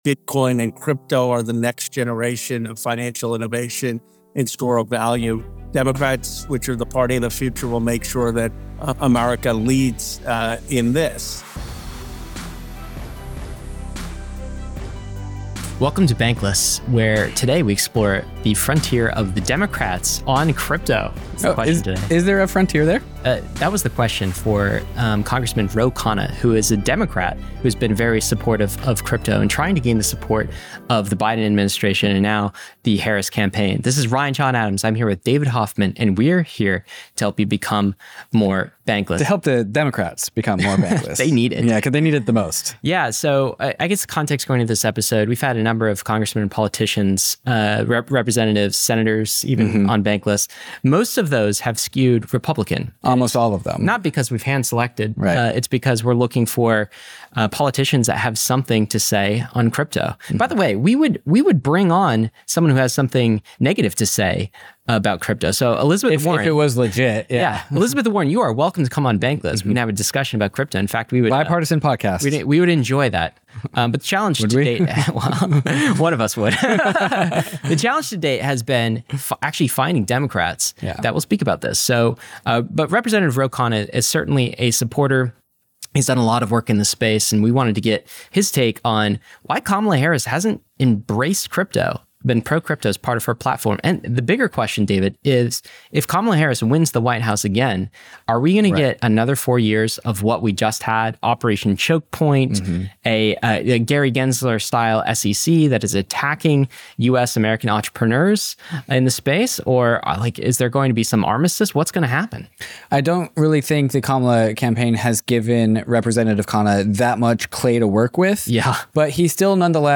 Crypto & The Democrats | Rep. Ro Khanna Podcast: Bankless Published On: Wed Sep 25 2024 Description: Representative Ro Khanna, a Congressman from California and a key advocate for crypto within the Democratic party joins us on today’s episode. We discuss his efforts to bring crypto into the mainstream, including recent meetings he's helped broker between the White House, the Kamala Harris campaign, and key figures from the crypto industry. We explore the challenges and opportunities for the Democrats to embrace pro-crypto policies, the potential future of crypto regulation, and how this could shape the party's platform moving forward.